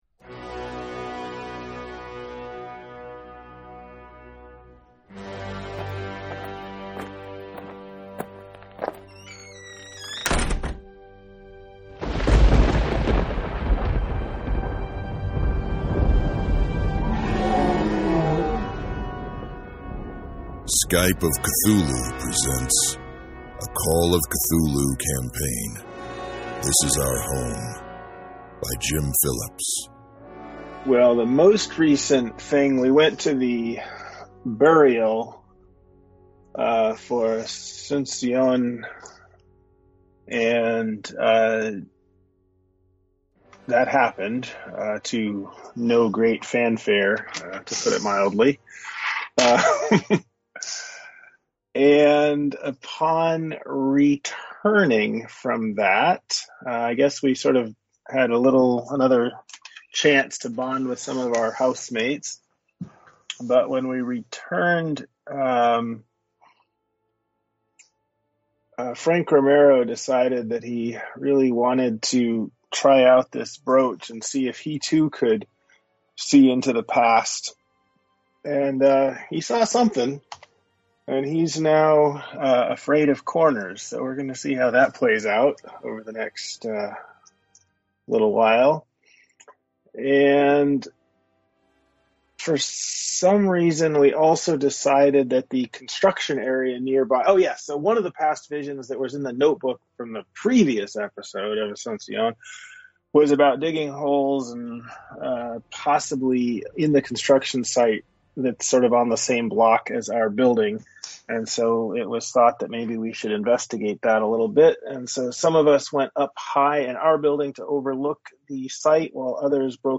Skype of Cthulhu presents a Call of Cthulhu scenario.